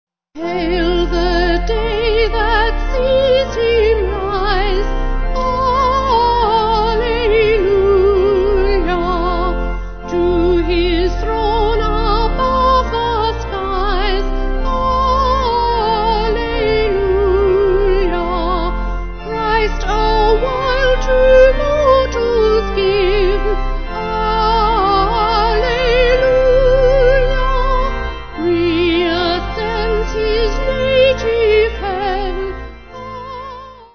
Vocals & Organ